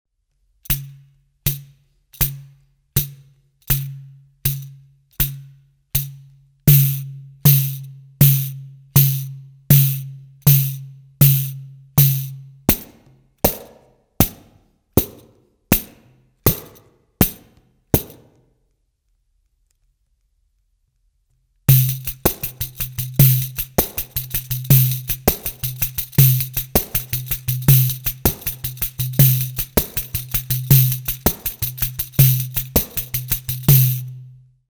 MEINL Percussion Traditional ABS Series Pandeiro - 10" - Nappa Head (PA10ABS-BK-NH)